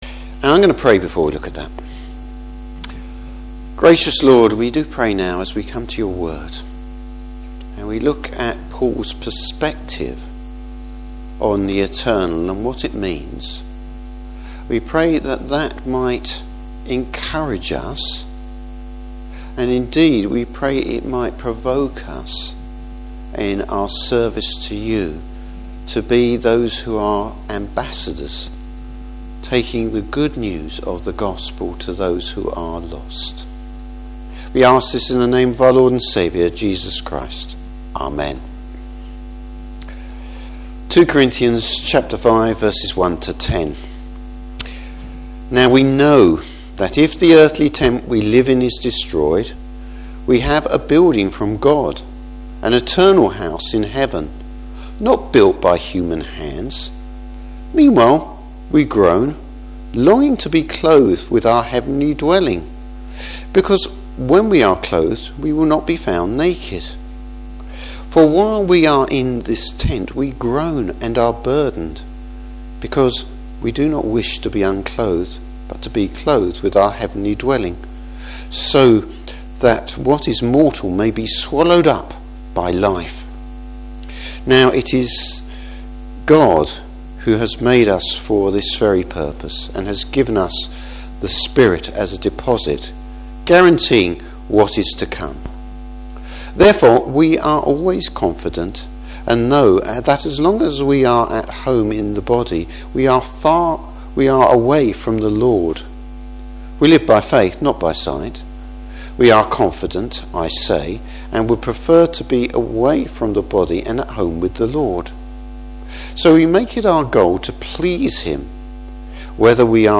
Service Type: Morning Service A guarantee for the future.